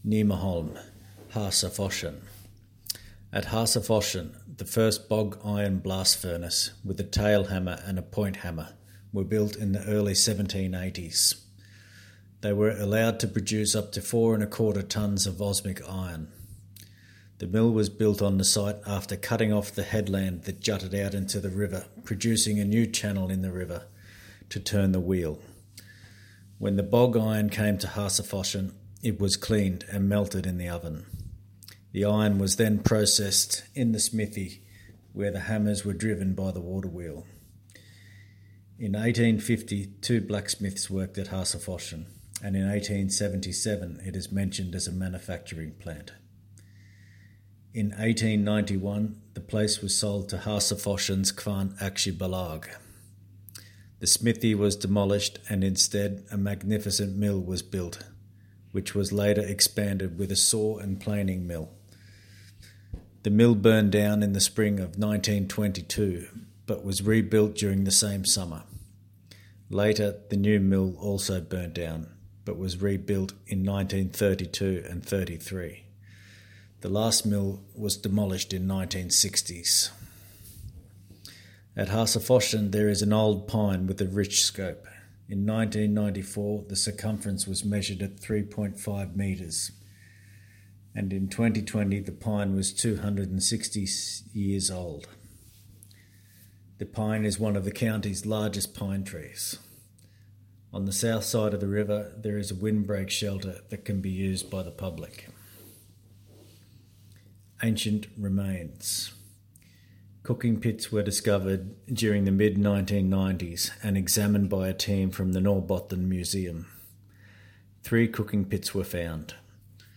Hasaforsen-English-voice.mp3